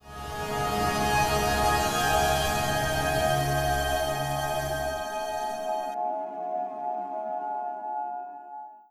Samsung Galaxy S160 Startup.wav